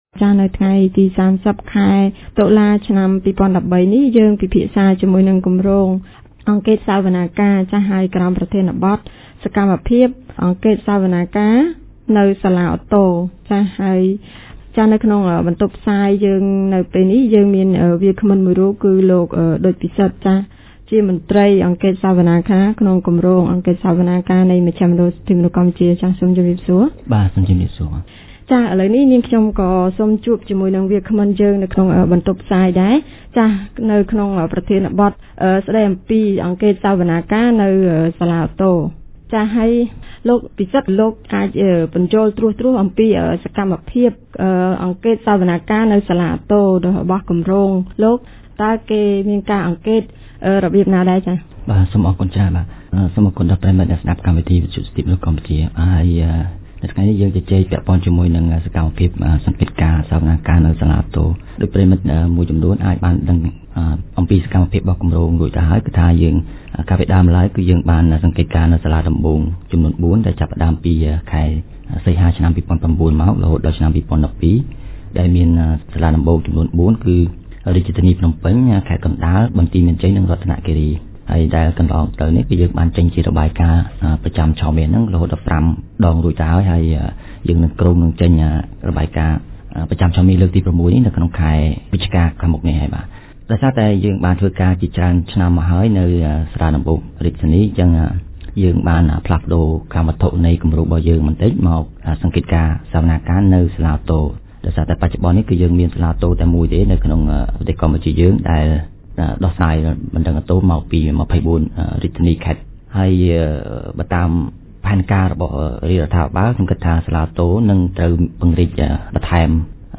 On 30 October 2013, TMP held a radio show presenting the ‘Trial Monitoring Activities at the Court of Appeal’.